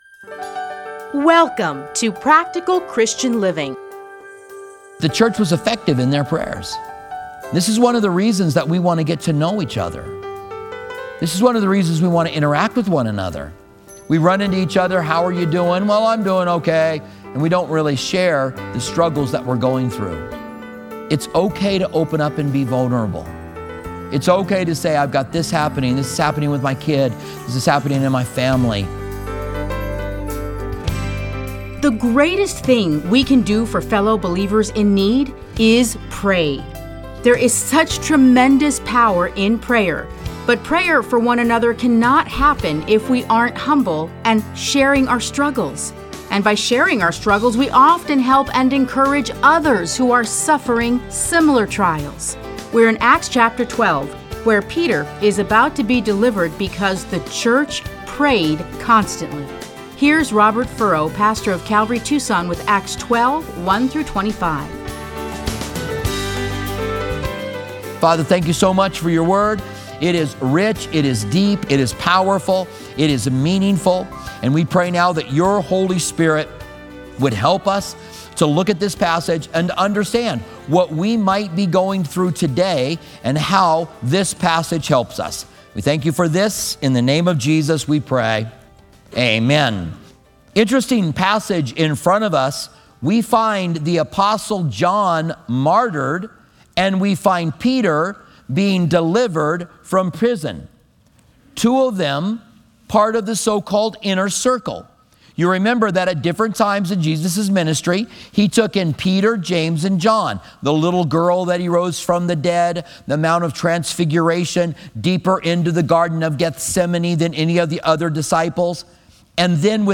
Listen to a teaching from Acts 12:1-24.